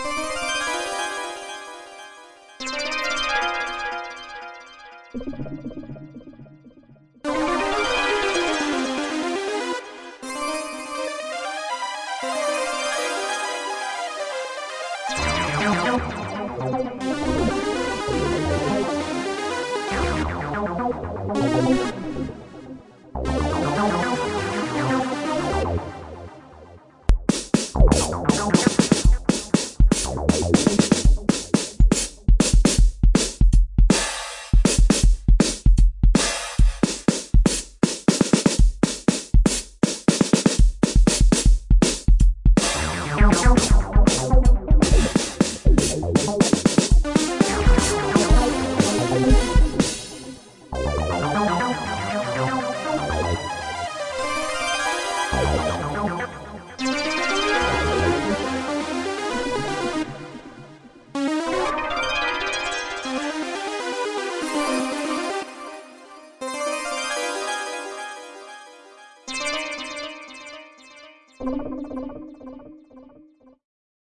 原声大鼓序列，NI Studio Drummer 120 BPM " 120 BPM Drum Loop 2
标签： 本地 军鼓 工作室 鼓手 混音 乐器 音响 雷鬼
声道立体声